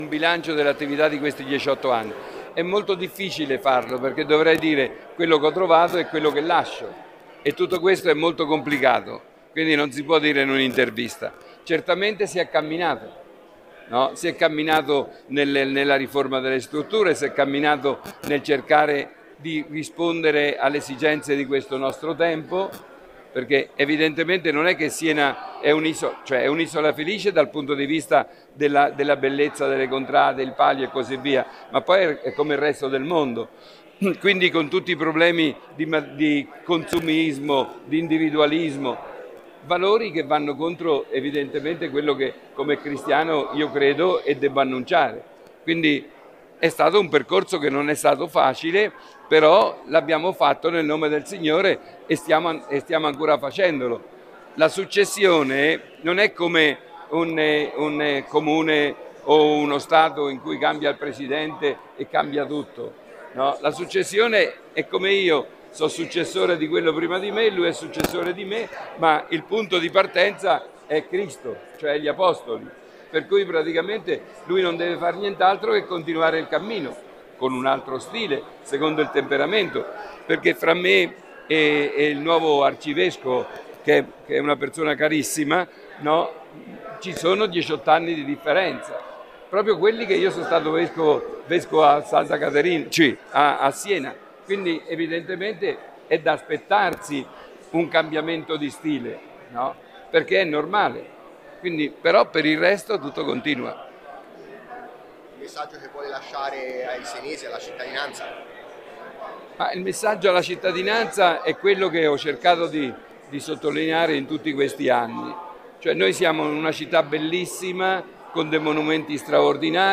Monsignor Antonio Buoncristiani lascia l’incarico di Arcivescovo di Siena dopo 18 anni di attività, molti rappresentanti dei maggiori enti senesi si sono riuniti nella Sala delle Lupe di Palazzo pubblico per salutarlo e rendergli omaggio.
Ai nosri microfoni l’arcivescovo uscente fa il bilancio di questi 18 anni, mentre il sindaco si prepara ad accogliere Paolo Lojudice e parla anche degli ultimi preparativi per l’imminente Palio di Luglio.
Buoncristiani: